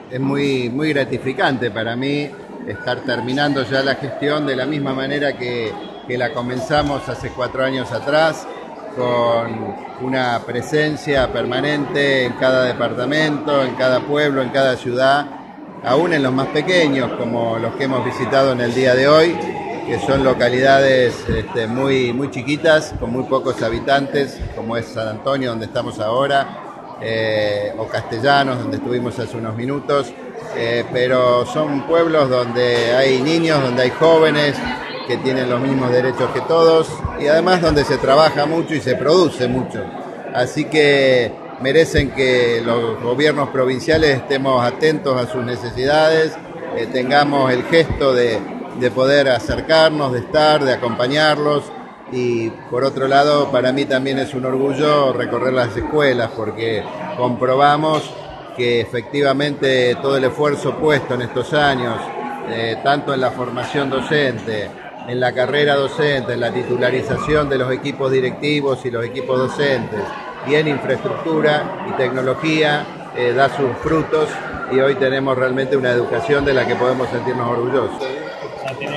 El gobernador Miguel Lifschitz visitó este viernes tres localidades del departamento Castellanos, donde recorrió instituciones educativas y se reunió con autoridades.
Palabras de Miguel Lifschitz